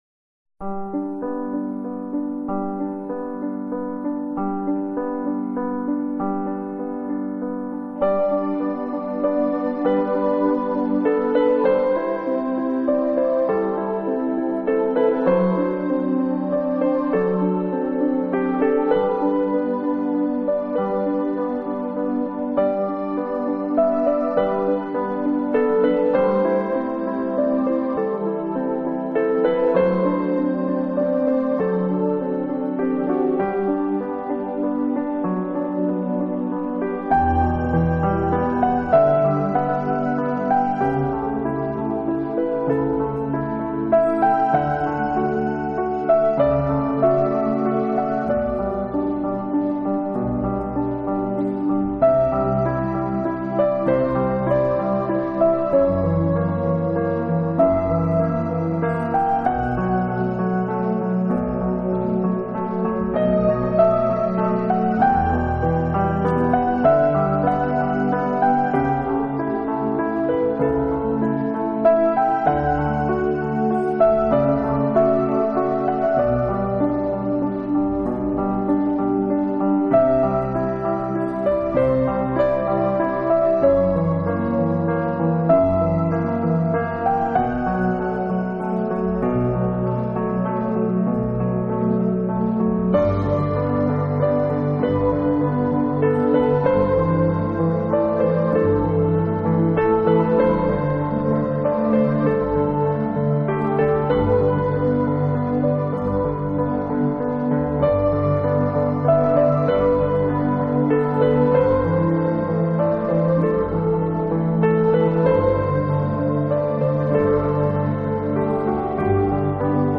Styles: New Age,Meditation,Adult Alternative